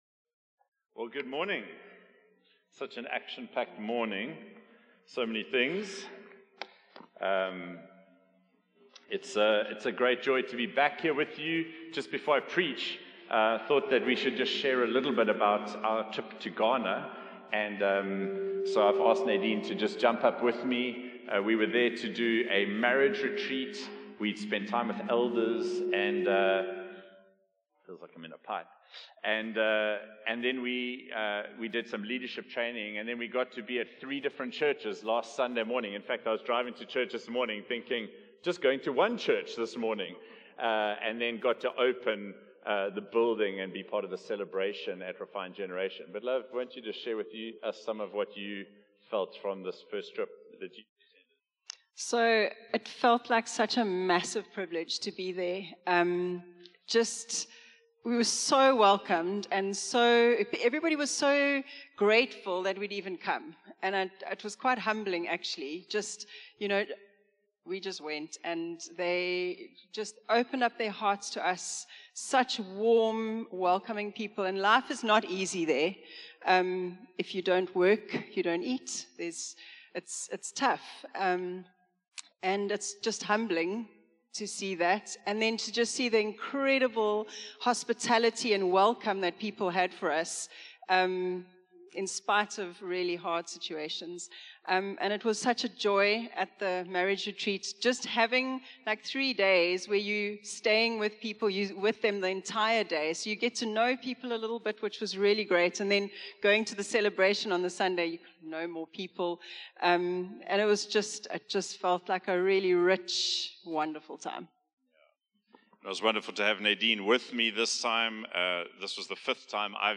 Far from abstract theology, the Father, Son, and Holy Spirit invite us into a dynamic relationship of love and joy. This sermon calls us to keep exploring God’s nature, embrace a full Trinitarian balance in our prayer & worship, avoid common theological errors, and enter the eternal “dance” of communion with God.